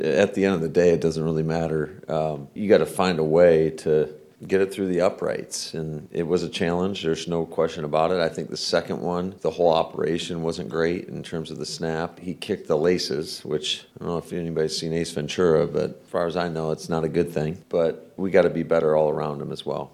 (LEARFIELD) – There was some good news coming out of Packers coach Matt LaFleur’s meeting with the media on Monday.